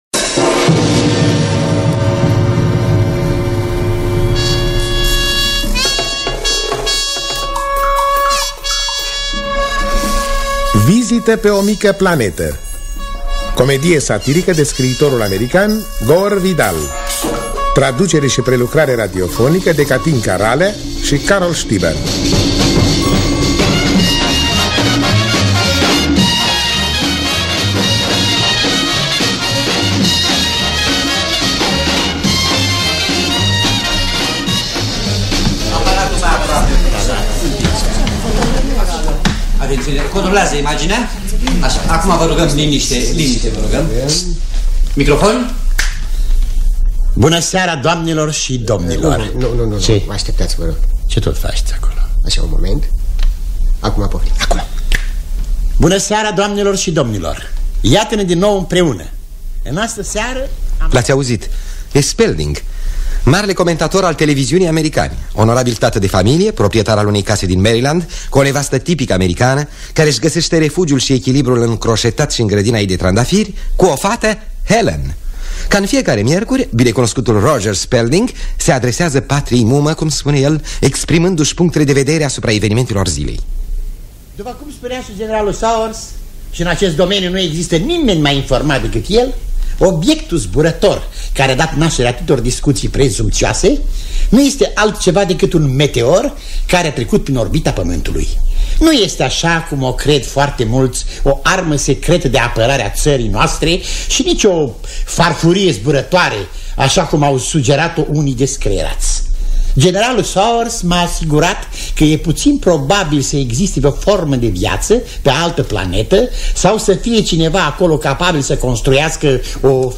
Traducere și adaptarea radiofonică de Catinca Ralea si Carol Stiber